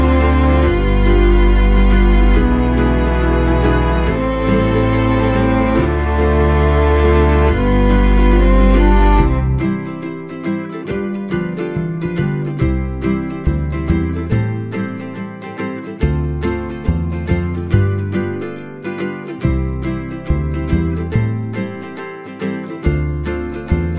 for duet Pop (2010s) 2:40 Buy £1.50